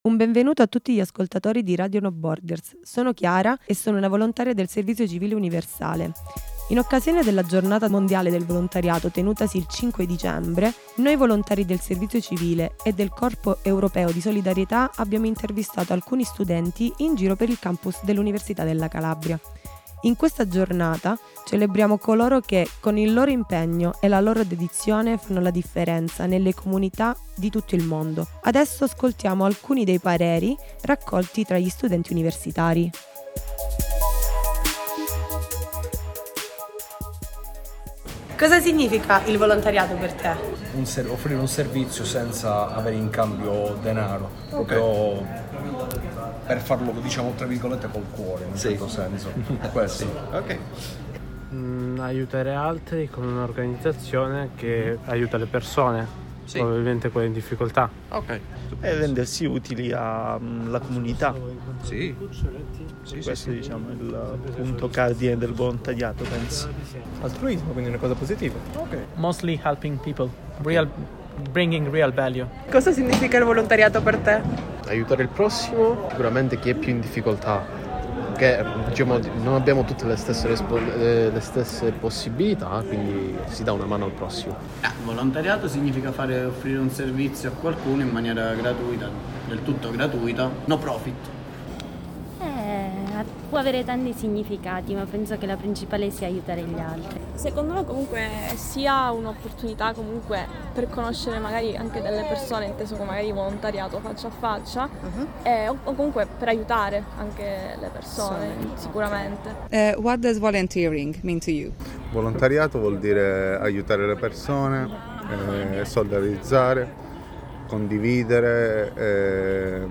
Ma che rapporto hanno i giovani con il volontariato? In occasione della giornata, i volontari del Servizio Civile Universale e dell’European Solidarity Corps che prestano servizio presso Entropia APS al DAM Unical, sono andati in giro per il Campus a raccogliere le opinioni degli studenti. Emerge la concezione del volontariato come un gesto totalmente altruista, dedicato all’aiuto verso i più deboli (come poveri, tossicodipendenti, bambini, donne vittime di violenza).
INTERVISTE-VOLUNTARY-DAY-FINITO.mp3